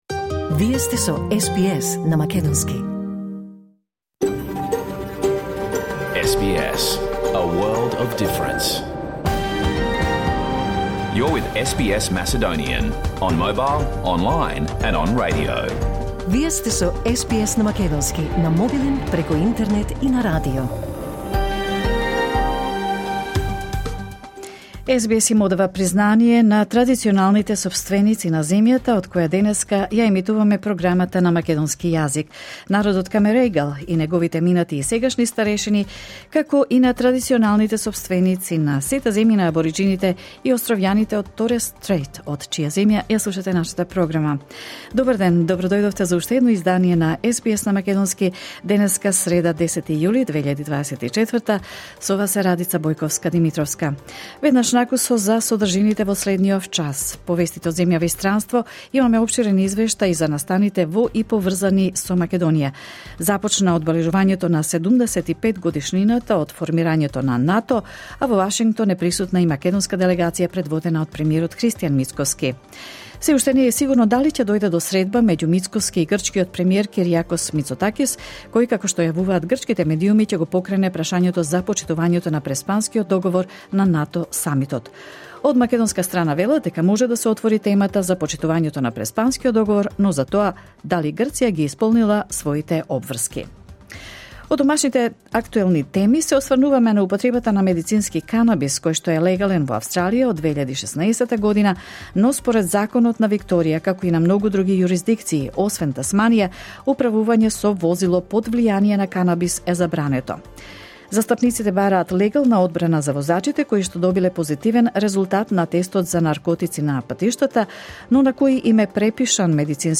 SBS Macedonian Progam Live on Air 10 July 2021